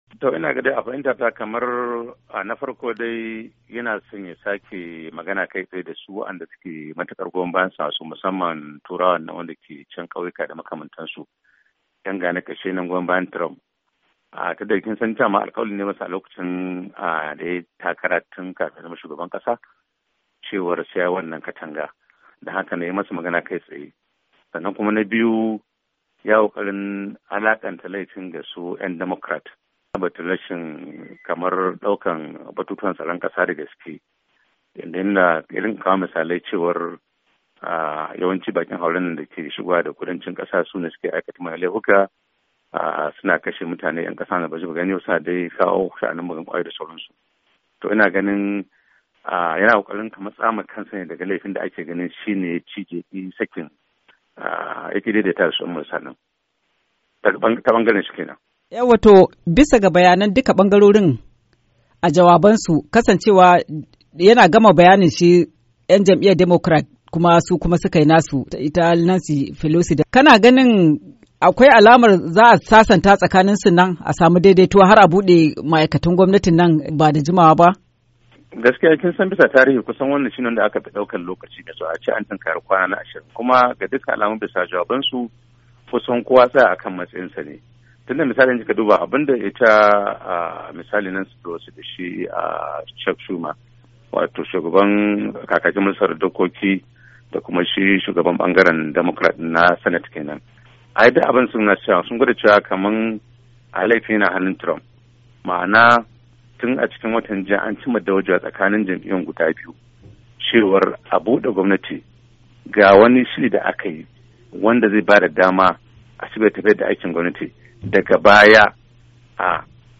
FASHIN BAKI A KAN JAWABIN TRUMP